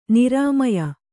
♪ nirāmaya